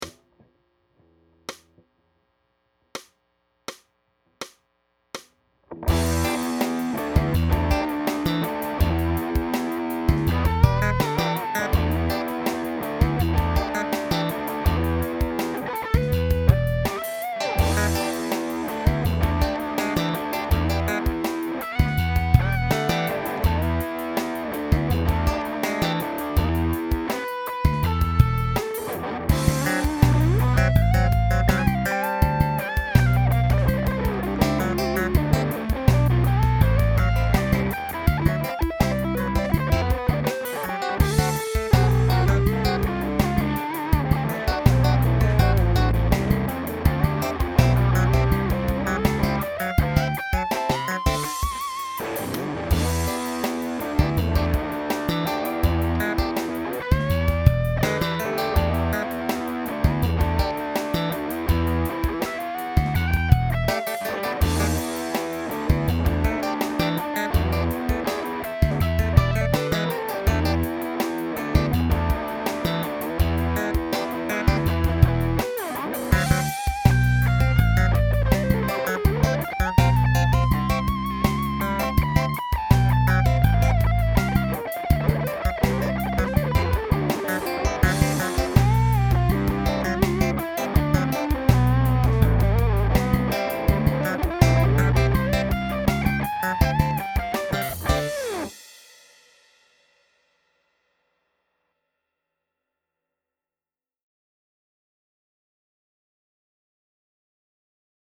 notated bass lines in the classic styles
31 - Funk.mp3